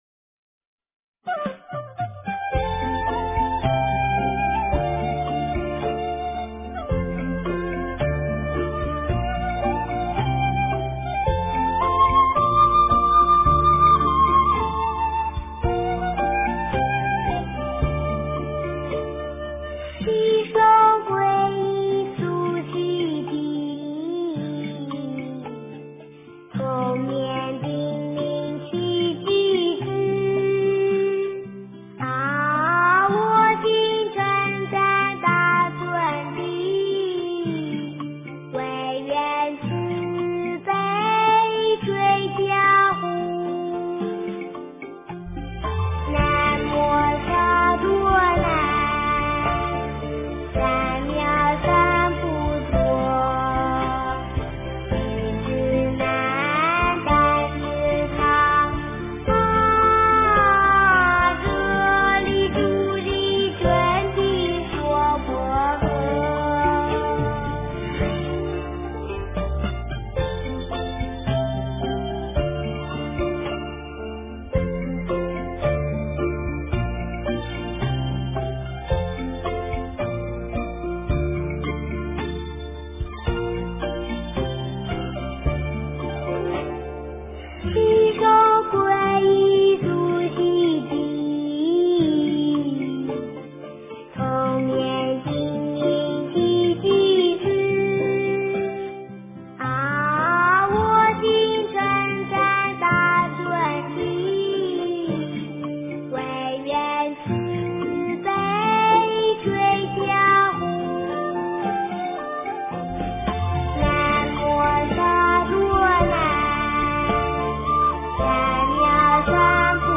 准提咒--佛音-童声 真言 准提咒--佛音-童声 点我： 标签: 佛音 真言 佛教音乐 返回列表 上一篇： 开经偈--梵音佛语 下一篇： 观世音菩萨--南无乐队 相关文章 六字大明咒--佚名 六字大明咒--佚名...